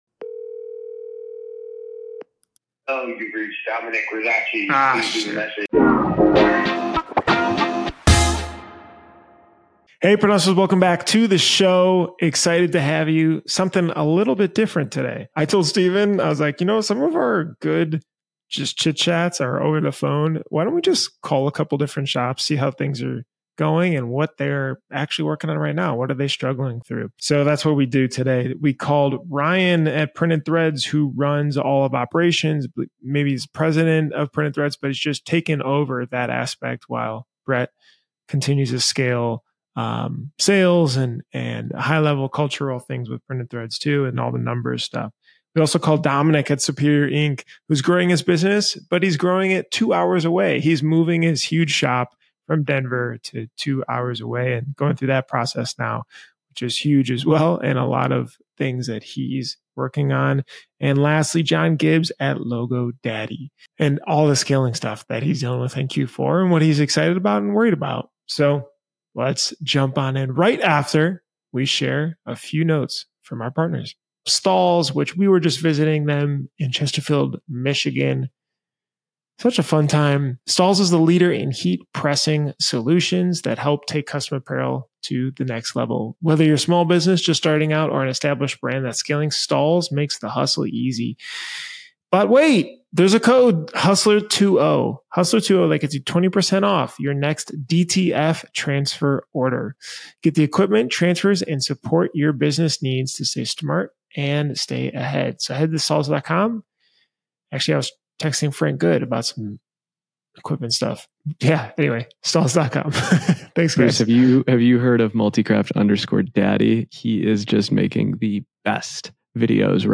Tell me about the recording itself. Live Phone Calls with Print Shop Owners: Hiring a Head of Ops, Moving a Big Shop & Q4 Growing Pains